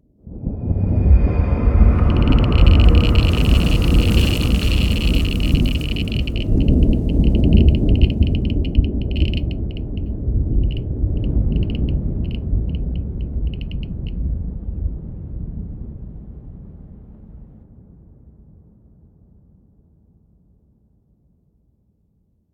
nuclear-explosion-aftershock-1.ogg